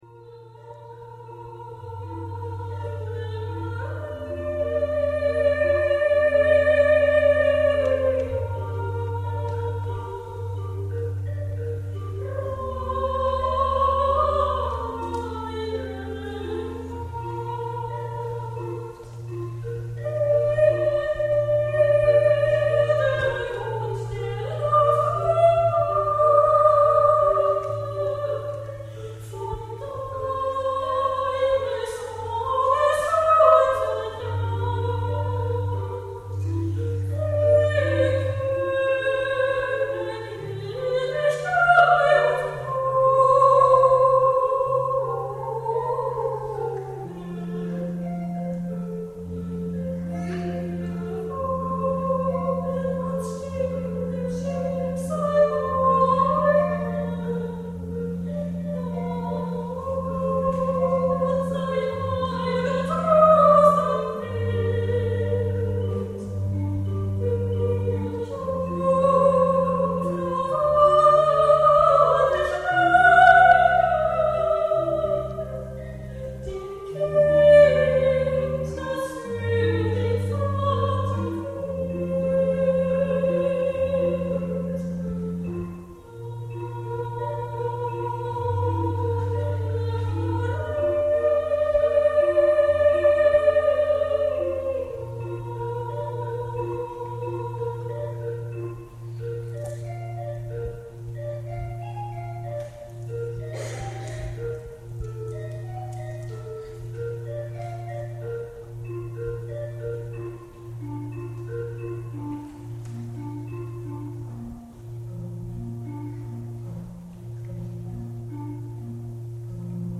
Ich bin Sopranistin und interpretiere sehr gern Klassische Musik, angefangen von Liedern und Arien alter italienischer Meister und Werken von J.S.Bach, G.F.Händel, über Kompositionen von W.A.Mozart, J.Haydn, F.Schubert, J.Brahms, G. Puccini, G.Verdi bis hin zu G.Gershwin - um nur einige Beispiele zu nennen.
live Ave Maria  Schubert 2008.mp3